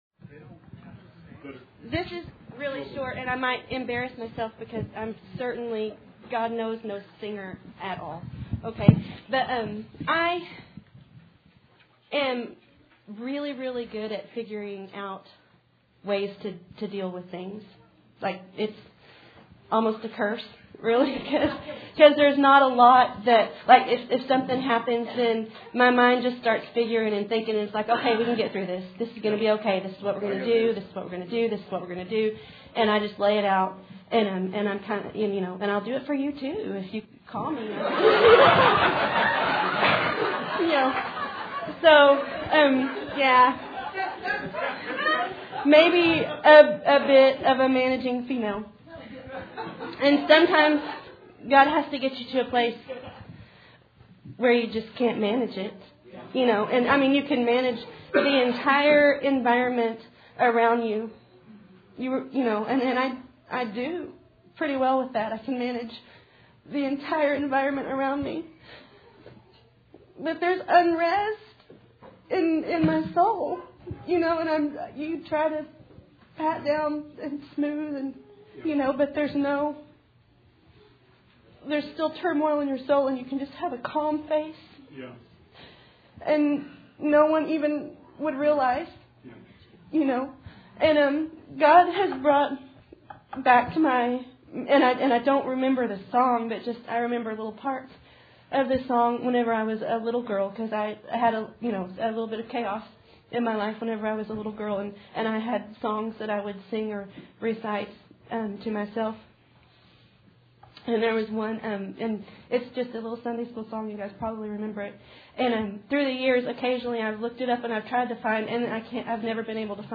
Sermon 10/27/19